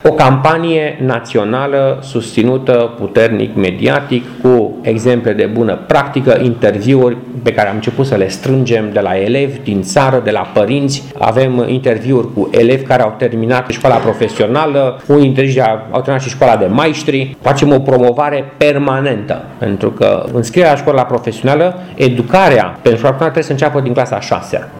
Printre oaspeţii prezenţi la Braşov, cu ocazia festivităţii legate de absolvirea celei de-a patra serii de elevi ai Şcolii Profesionale Germane Kronstadt, s-a aflat şi consilierul de stat Stelian Victor Fedorca, o persoană care se ocupă de învăţământul dual din 2012, când îndeplinea funcţia de secretar de stat în Ministerul Educaţiei.
Oficialul guvernamental a explicat cum trebuie procedat, pentru ca învăţământul dual din România, singurul care pregăteşte viitorii meseriaşi, să câştige tot mai mult teren: